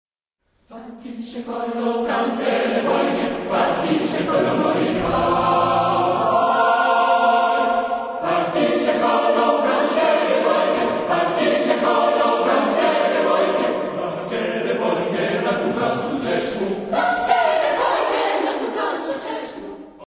Air populaire serbe
Genre-Style-Form: Popular ; Dance ; Secular ; Round ; Partsong
Type of Choir: SATB  (4 mixed voices )
Discographic ref. : Brasil 2002 Live